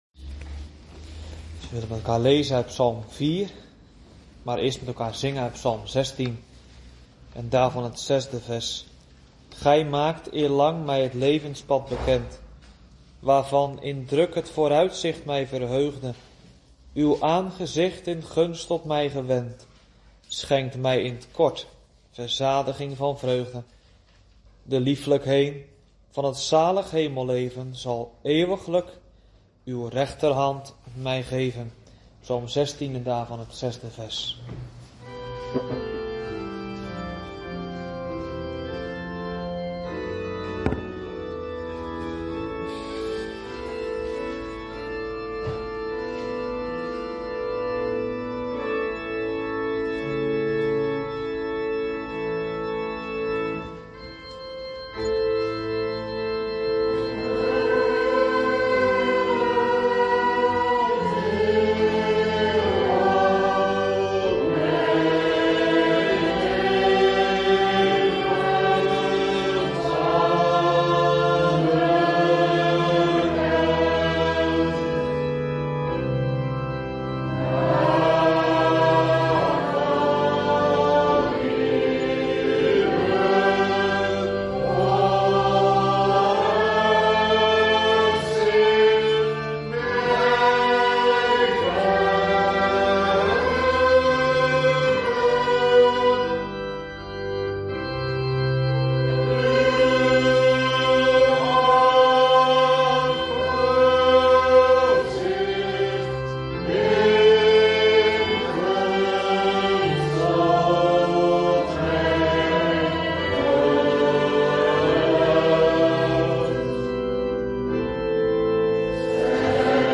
LEZING 31 - DORDTSE LEERREGELS - H5, ART. 1-3- DE DAGELIJKSE ZONDEN DER ZWAKHEID, DE GEBREKEN VAN DE HEILIGEN EN GODS TROUW